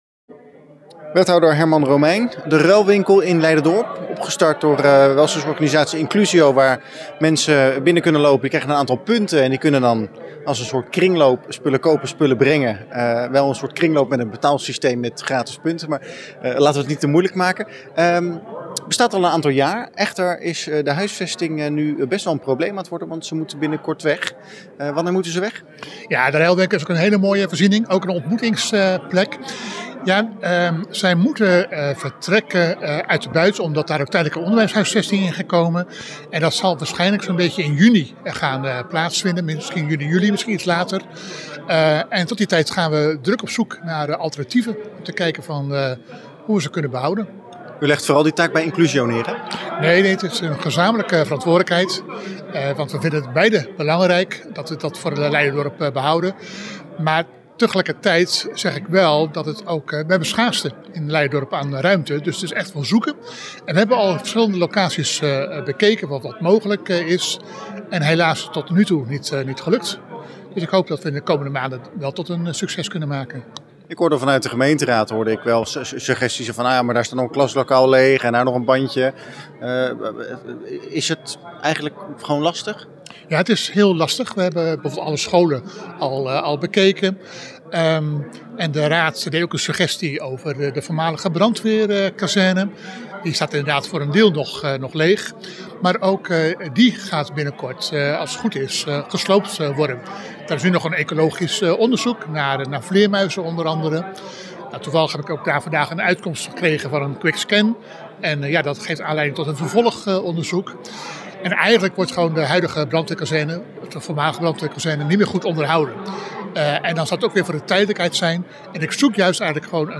Wethouder Herman Romeijn over de huisvestingsproblemen van de Ruilwinkel Leiderdorp.